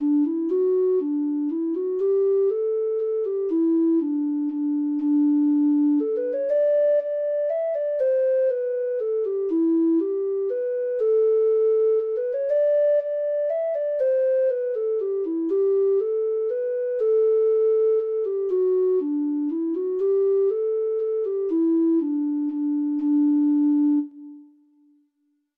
Treble Clef Instrument Traditional Treble Clef Instrument Traditional Treble Clef Instrument Free Sheet Music The Ashtree Grove (Irish Folk Song) (Ireland)
Irish